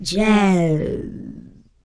06 RSS-VOX.wav